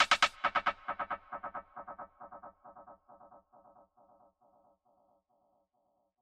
RIM X3.wav